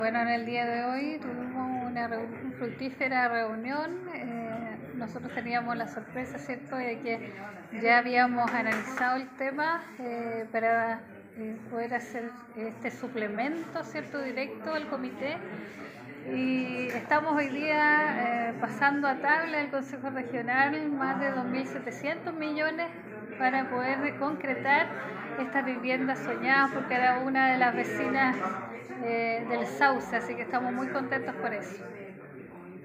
Por su parte la Gobernadora Regional, Krist Naranjo precisó
CUNA-GOBERNADORA-REGIONAL-KRIST-NARANJO.mp3